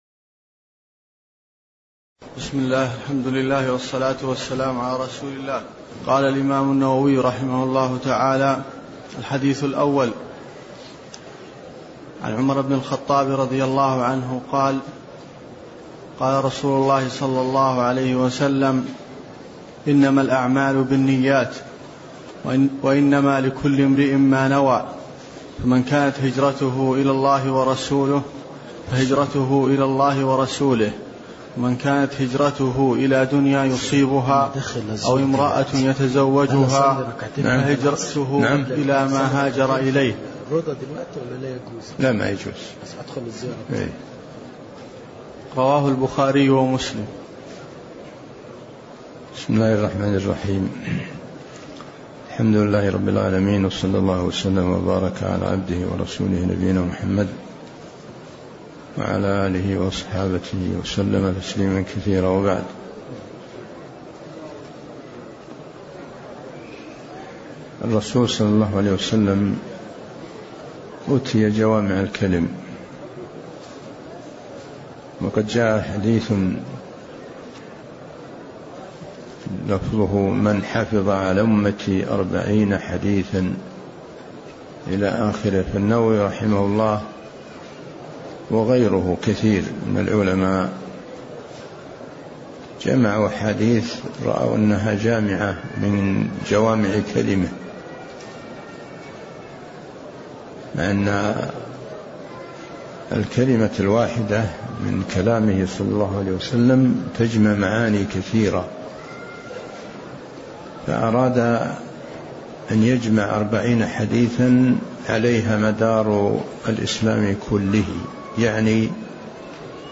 تاريخ النشر ١٤ شعبان ١٤٢٩ هـ المكان: المسجد النبوي الشيخ